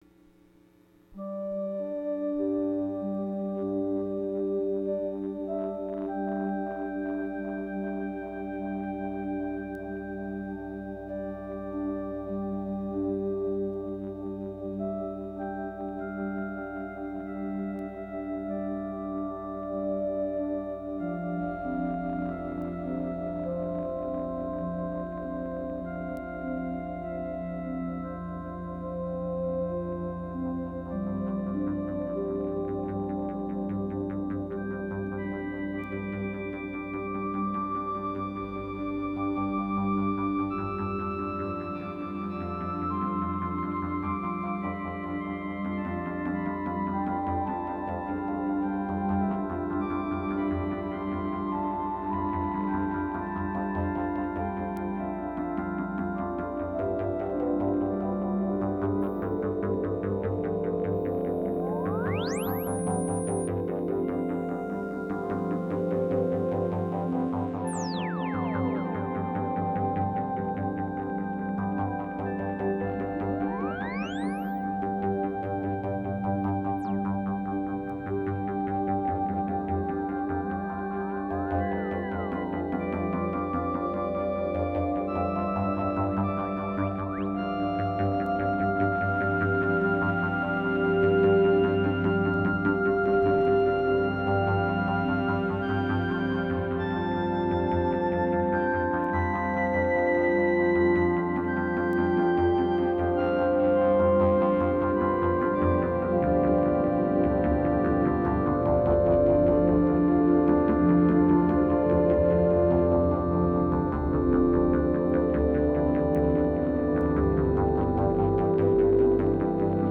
Yamaha, Moog, Memory Man